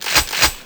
Shotgr1b.wav